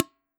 IronBucket1.wav